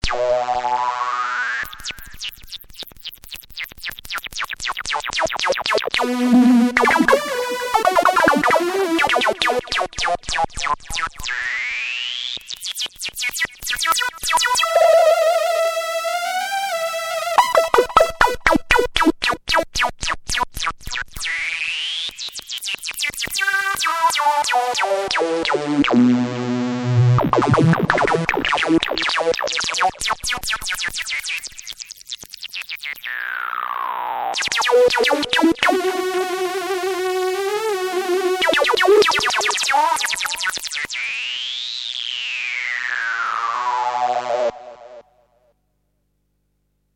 next clips featuring just the suboscillators - their waveforms are more conventional than the main vcdo output, and lend themselves to some subtractive synthesis.
2 x waverider subs through a multimode in hpf mode